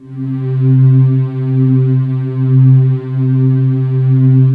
Index of /90_sSampleCDs/USB Soundscan vol.28 - Choir Acoustic & Synth [AKAI] 1CD/Partition C/10-HOOOOOO
HOOOOOO C2-L.wav